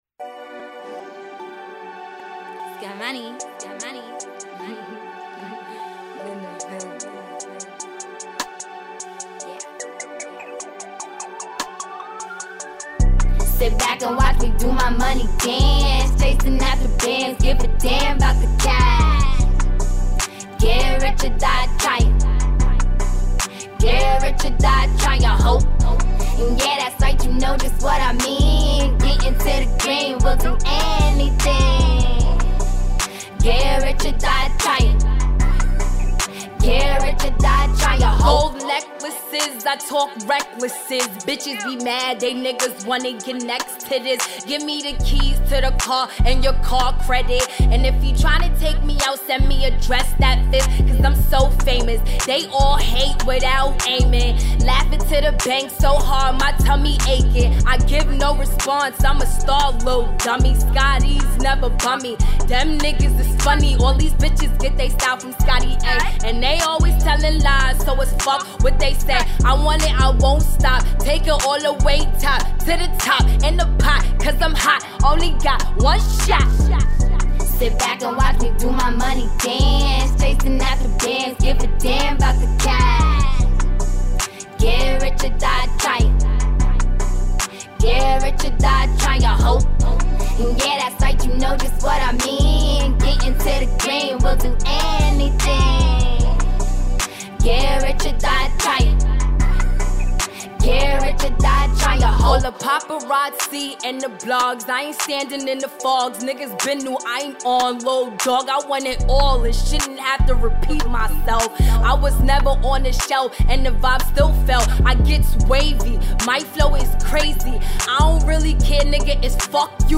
femcees
which has the hallmarks of a hip-hop banger.
A melodious beat flows with the chorus